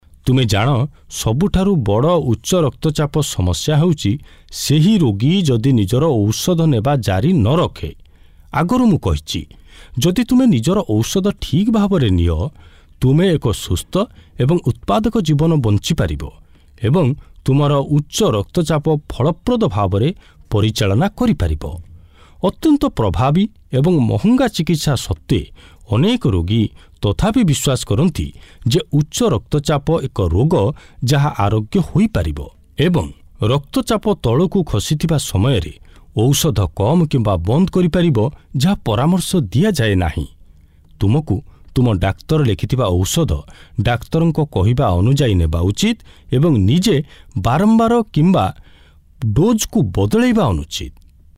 Oriya Voice Over Sample
Oriya Voice Over Male Artist 1